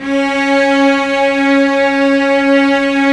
Index of /90_sSampleCDs/Roland LCDP13 String Sections/STR_Vcs II/STR_Vcs6 f Amb